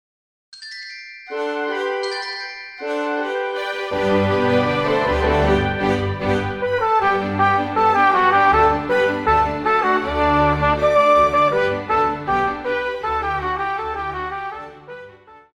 Classical
Trumpet
Orchestra
Instrumental
Only backing